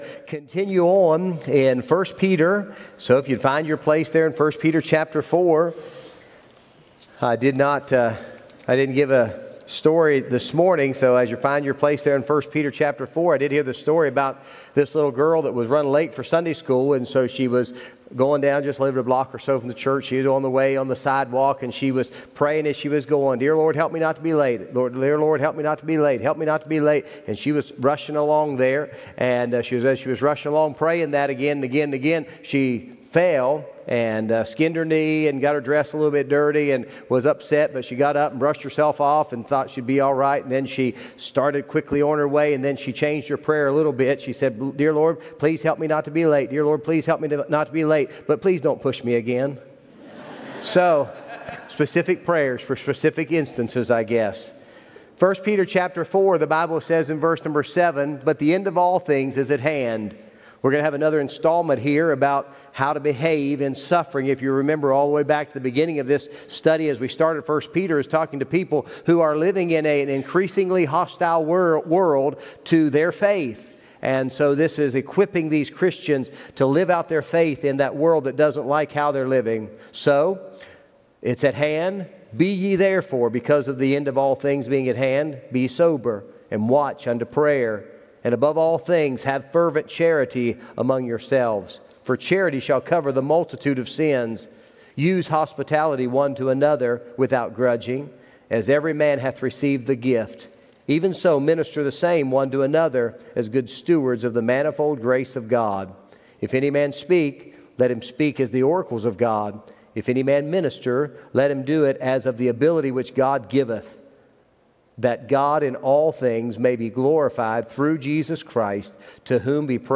Sunday Evening Service